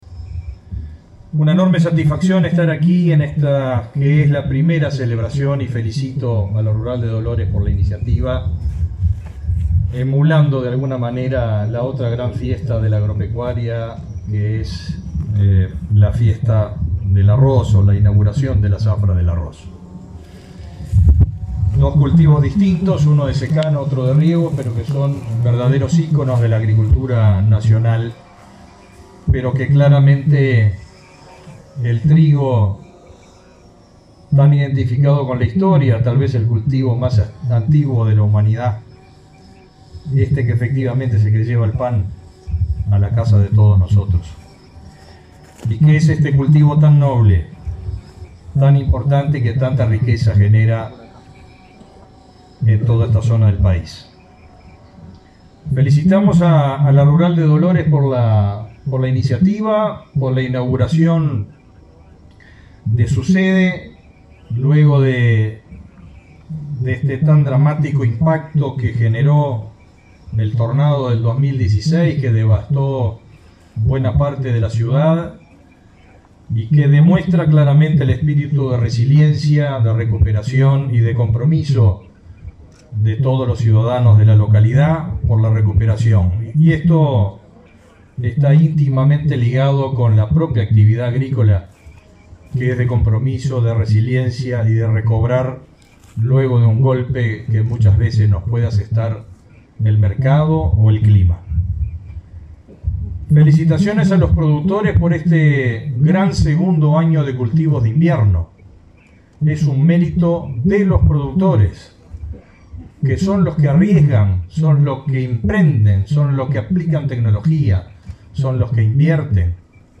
Palabras del ministro de Ganadería, Fernando Mattos
El ministro de Ganadería, Fernando Mattos, participó este martes 16 en Dolores, Soriano, en la inauguración de la cosecha de trigo convocada por la